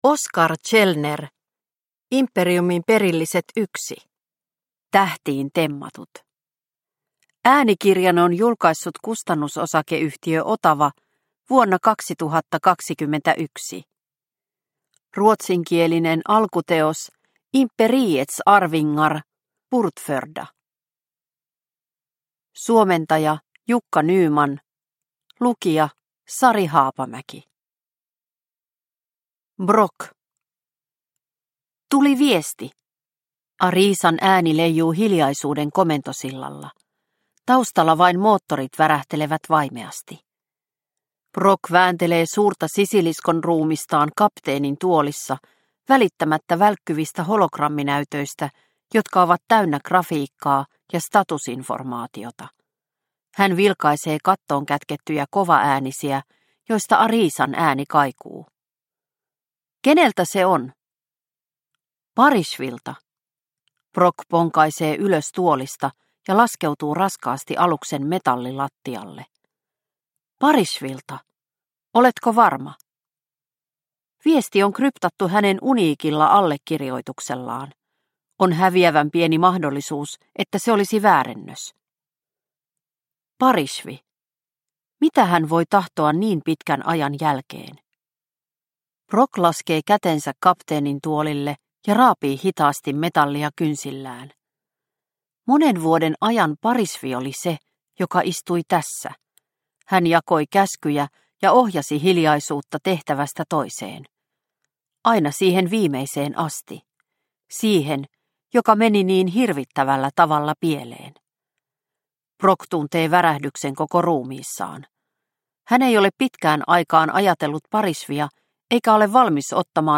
Imperiumin perilliset 1 Tähtiin temmatut – Ljudbok – Laddas ner